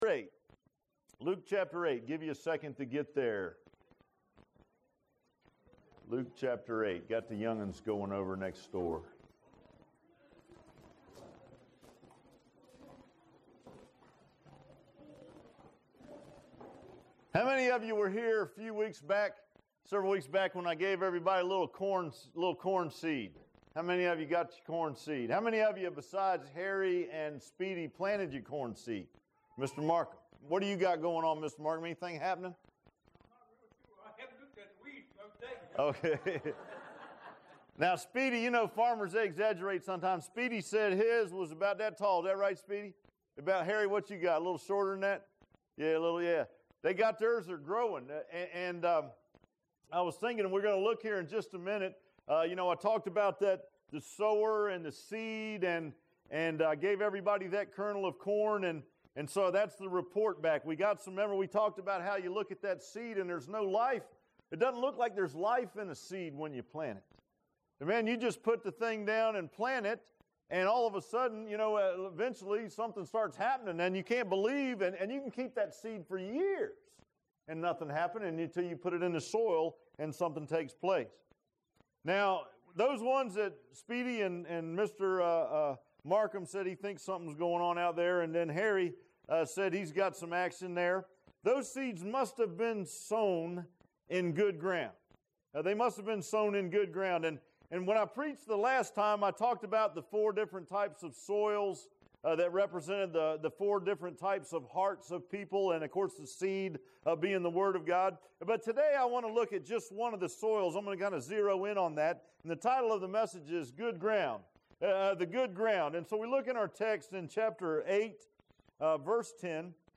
Luke 8:10-15 Service Type: Sunday AM Bible Text